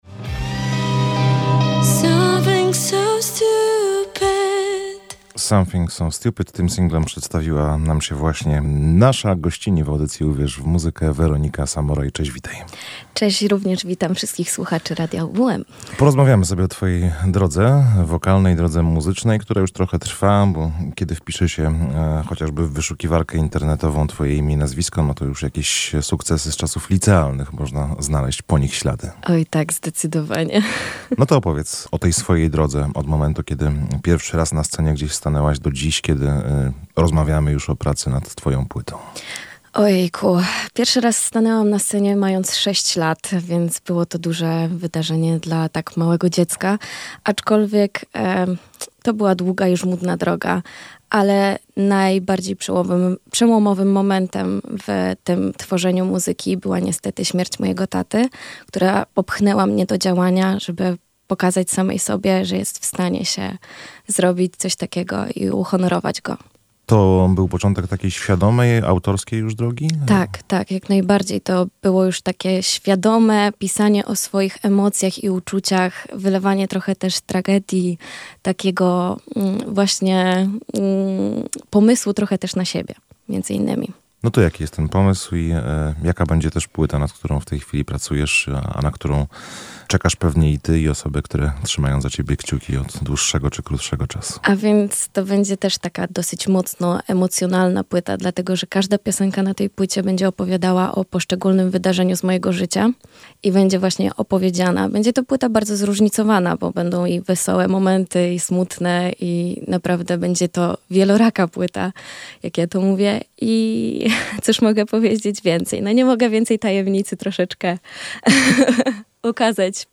Zaczęłam wtedy świadomie pisać o swoich emocjach i uczuciach” – mówiła w studiu Radia UWM FM.